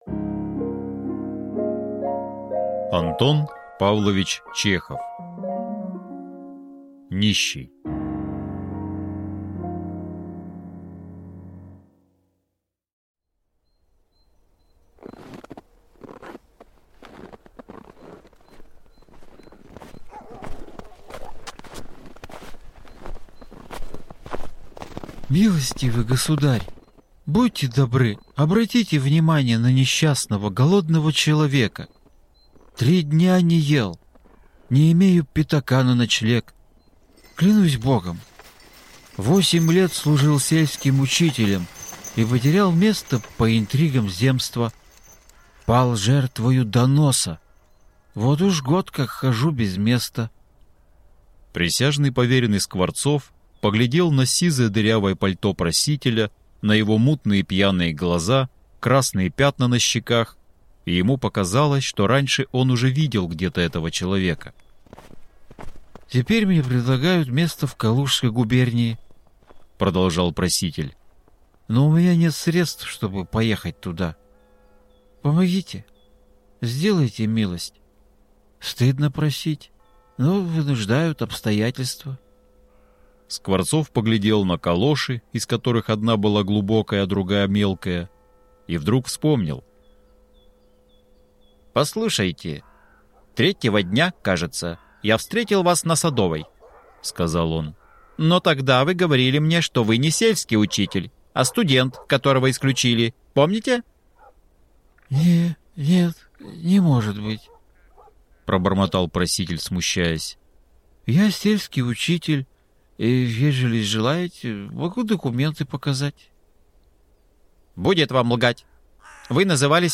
Аудиокнига Нищий | Библиотека аудиокниг